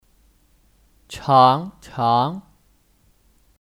常常 (Chángcháng 常常)